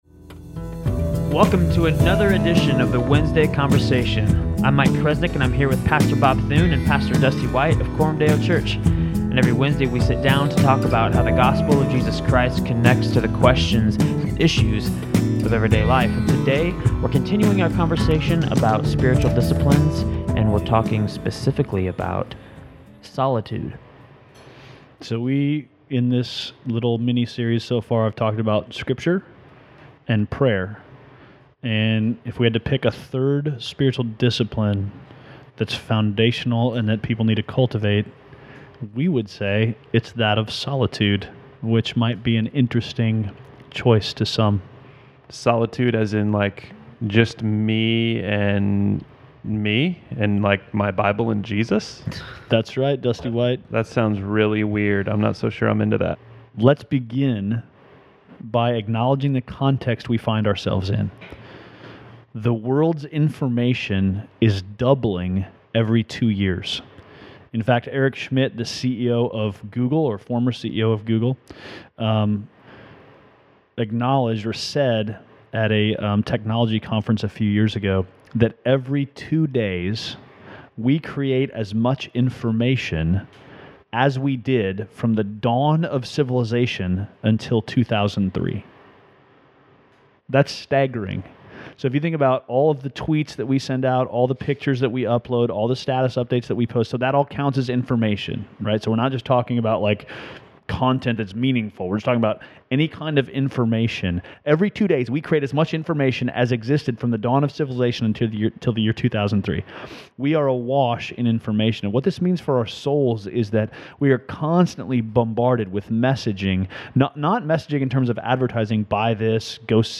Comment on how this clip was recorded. We start the New Year off right with a new podcast studio (echoes and construction sounds are free...) and a new topic of conversation, Spiritual Disciplines.